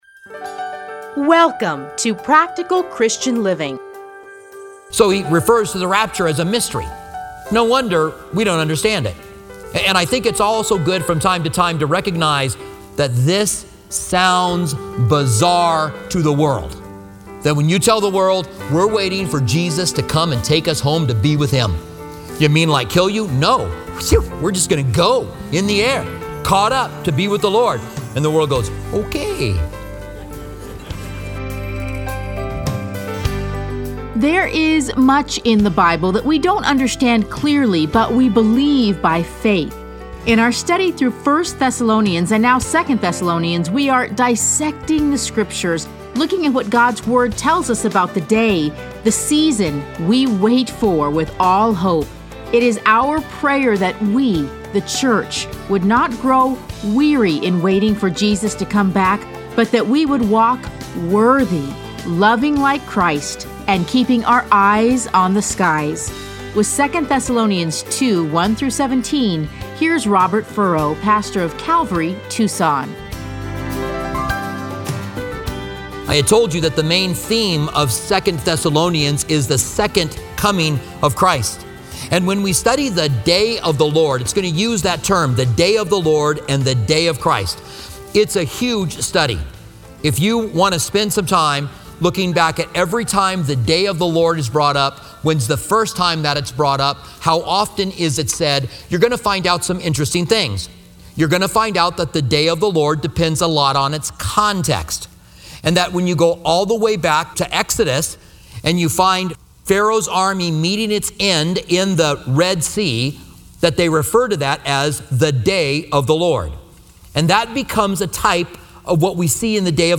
Listen to a teaching from 2 Thessalonians 2:1-17.